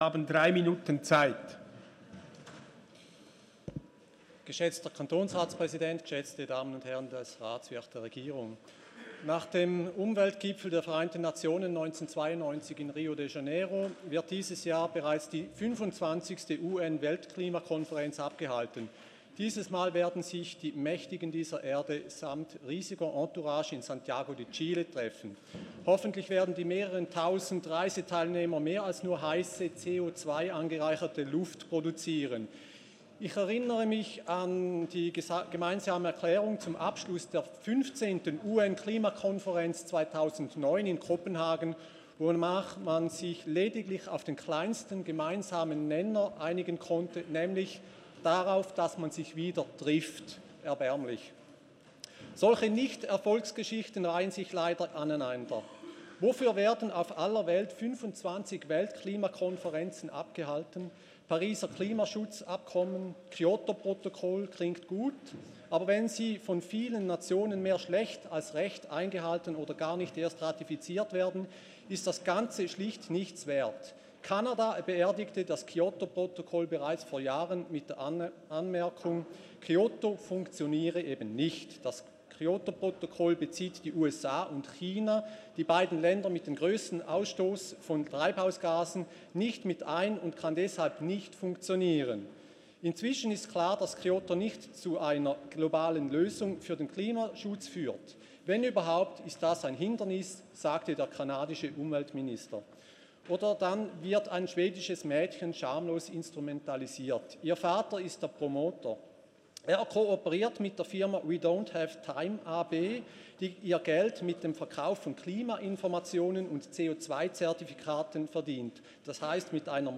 13.6.2019Wortmeldung
Session des Kantonsrates vom 11. bis 13. Juni 2019